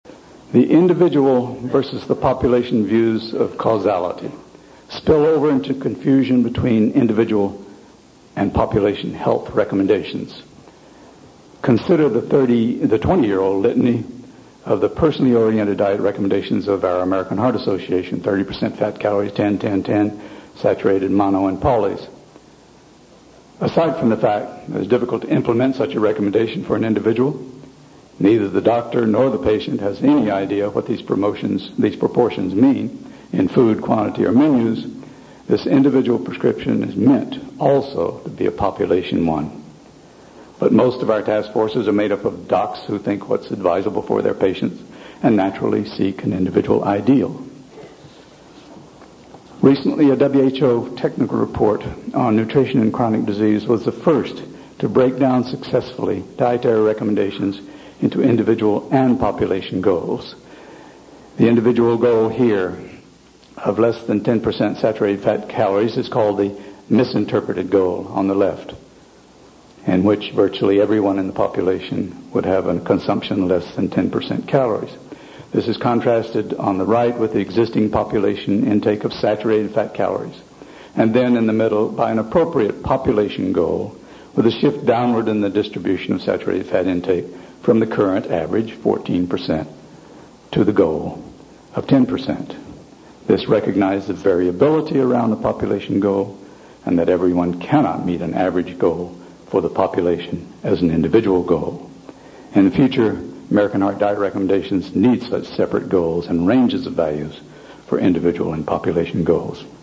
These audio segments are extracts from the Ancel Keys Lecture at the American Heart Association Meeting in Anaheim in 1991, a crossroads period in CVD epidemiology during which the built-in balance of NHLBI programs was heavily affected by funding and policy changes.